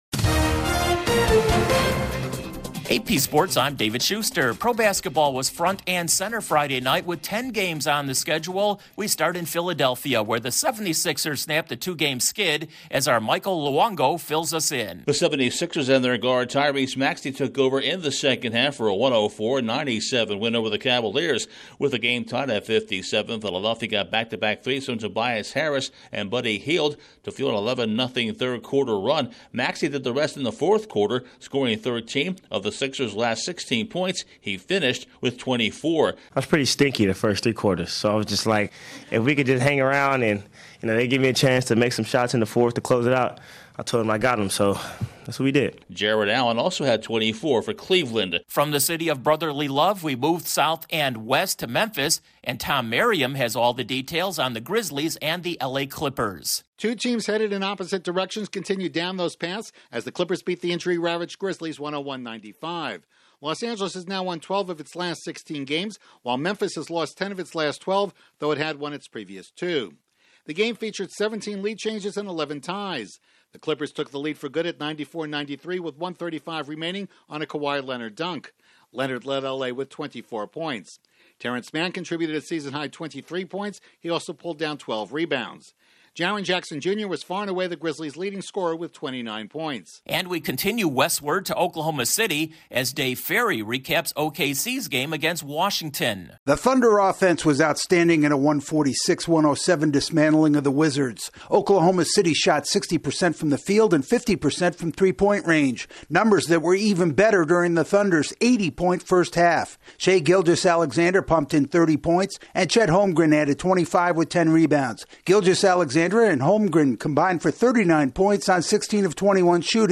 Pro Basketball dominates the headlines with 10 games on tap, just three games in the NHL and they were all close affairs, the NFL salary cap is going up and a recent pro football Hall of Fame electee is released from the hospital. Correspondent